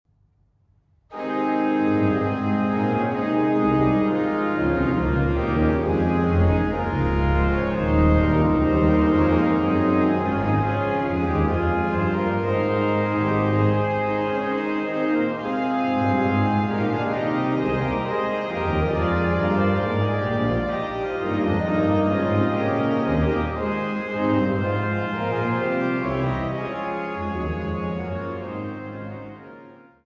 in Canone all' Ottava